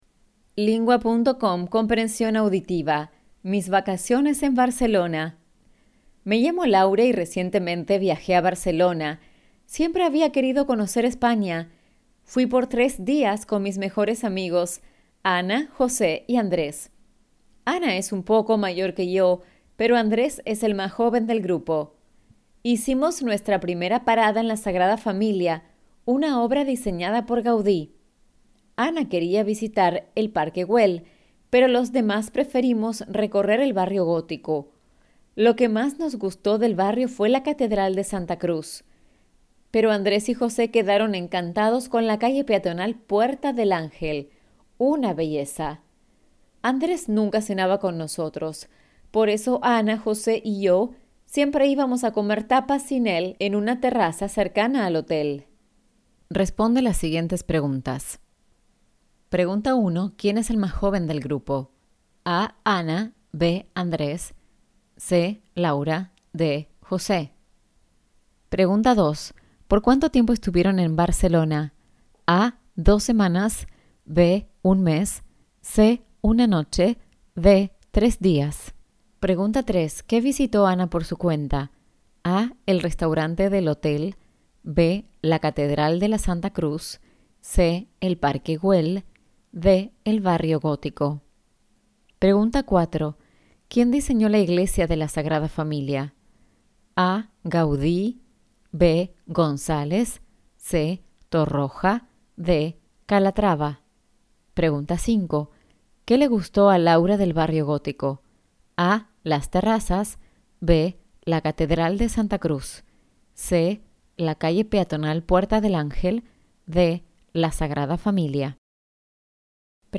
Argentine